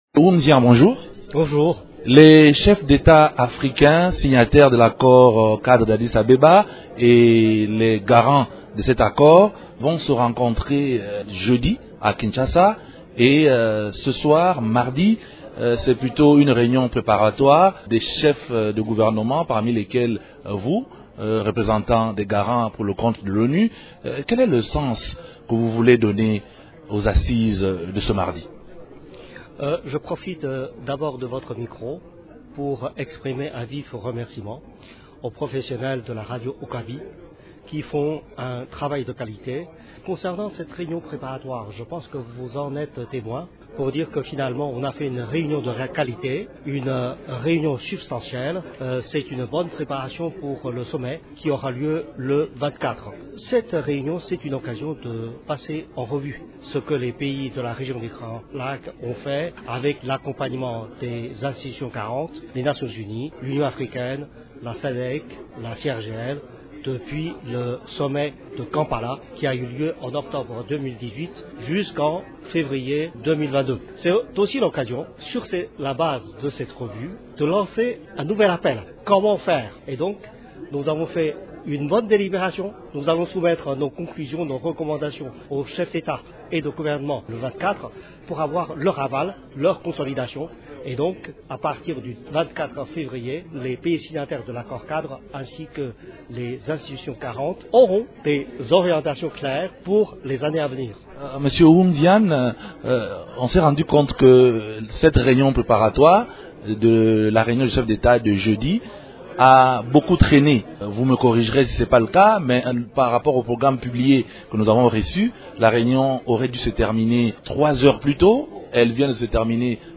L’Envoyé spécial du secrétaire général de l’ONU dans la région des Grands lacs, Hyung Xia, y répond dans cet entretien